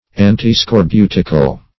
Antiscorbutical \An`ti*scor*bu"tic*al\, a.